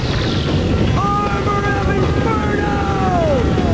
Ryo, summoning the armor of Inferno